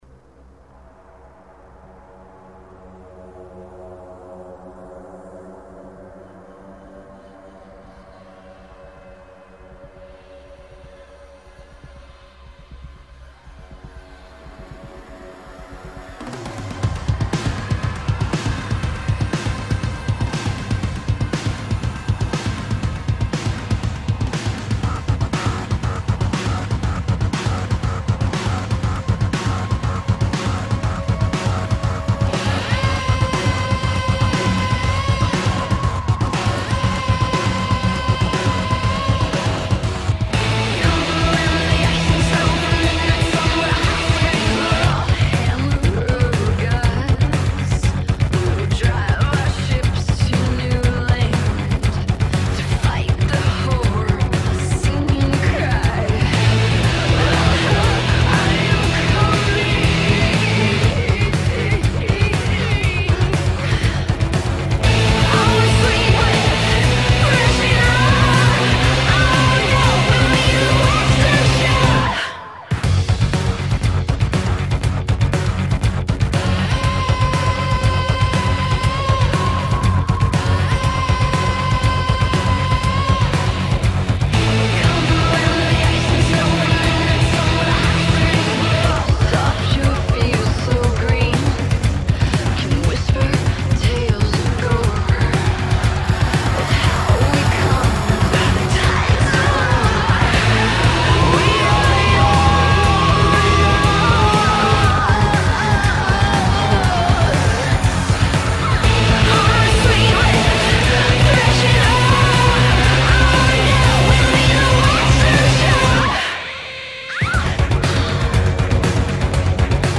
Wonderfully savage.